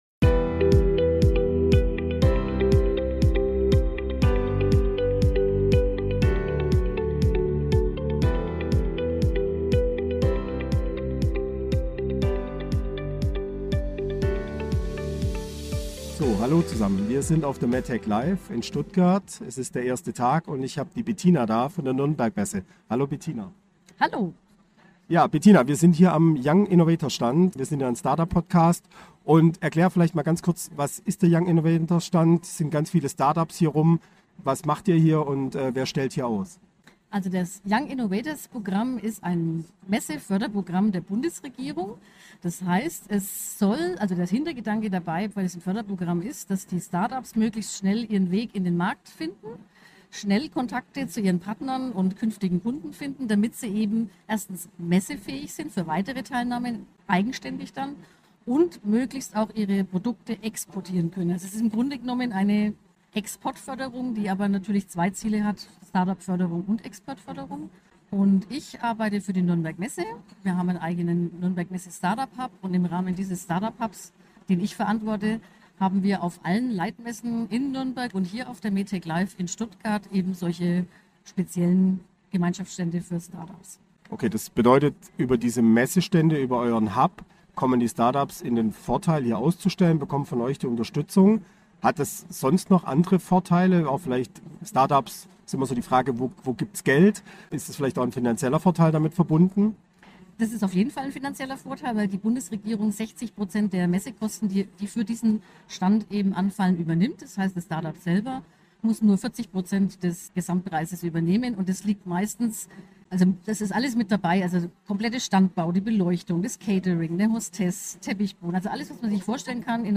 waren auf der MedTecLive mit einem eigenen Podcast Stand vor Ort und haben die spannendsten Eindrücke für euch festgehalten.